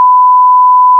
sin_mono.wav